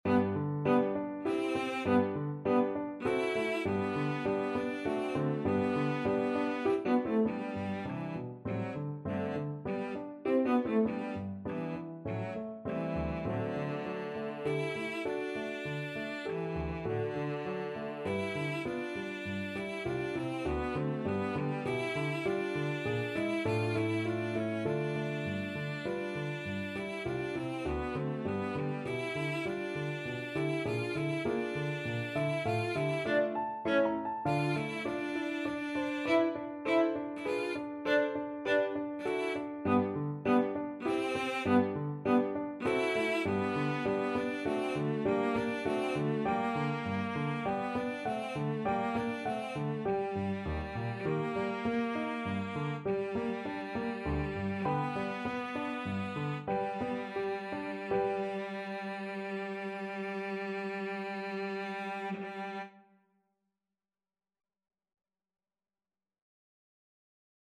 Classical Brahms, Johannes Symphony No.2, 3rd Movement Main Theme Cello version
Cello
G major (Sounding Pitch) (View more G major Music for Cello )
~ = 100 Allegretto grazioso (quasi Andantino) (View more music marked Andantino)
3/4 (View more 3/4 Music)
D4-F5
Classical (View more Classical Cello Music)